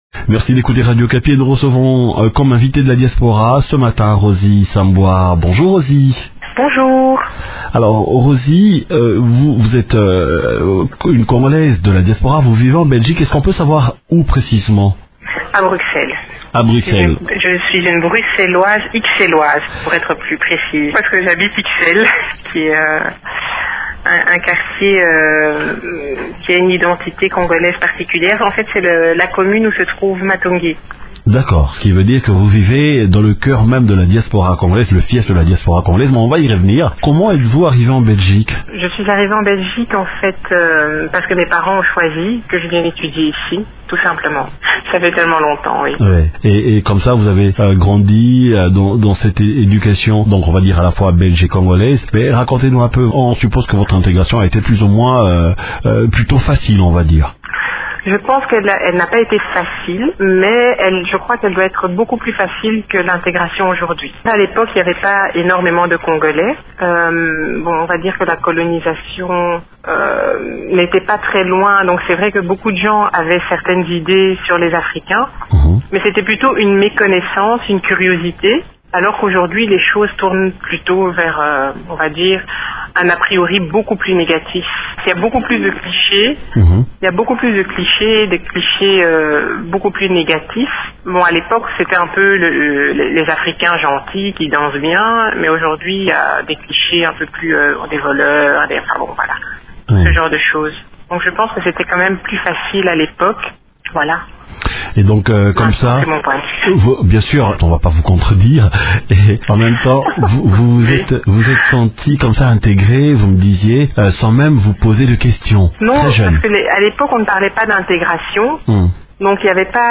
Elle nous parle de son parcours et de son expérience au micro